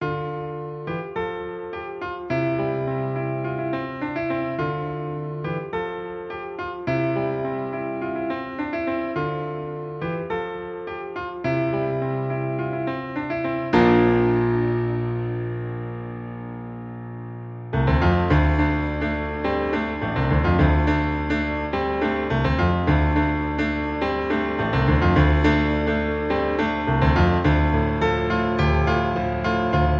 • 🎹 Instrument: Piano Solo
• 🎼 Key: D Minor
• 🎶 Genre: Rock